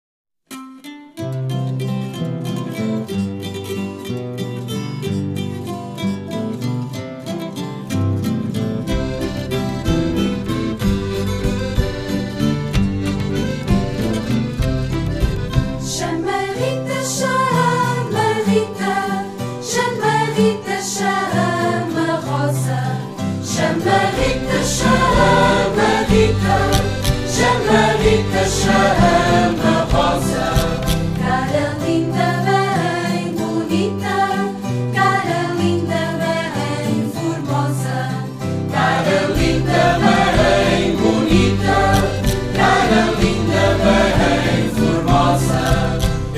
Algumas cantigas do Açores
Gentilmente cedidas pelo excelente "Grupo de Cantares Belaurora" de São Miguel, Açores, Pt.